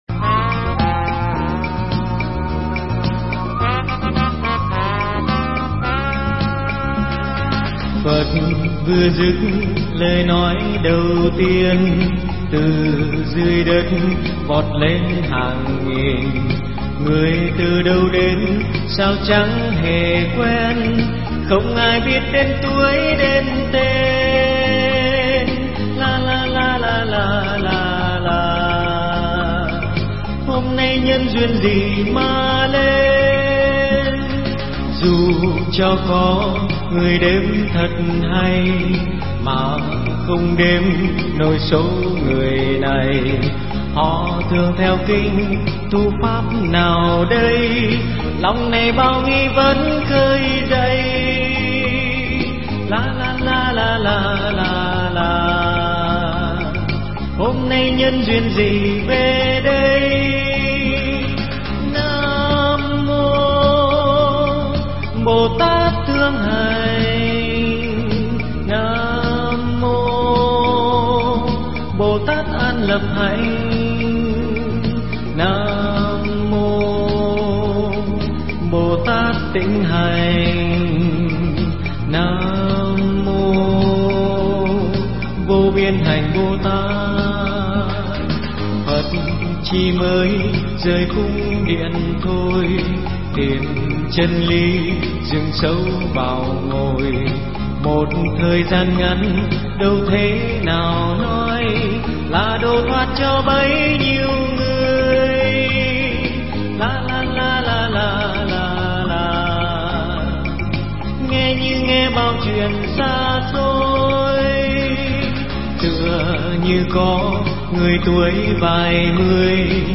Mp3 Pháp Thoại Kinh Dược Sư Giảng Giải phần 4 – Hòa Thượng Thích Trí Quảng Giảng tại Ấn Quang Tự, quận 10, ( ngày 20 tháng 5 Định Hợi), ngày 4 tháng 7 năm 2007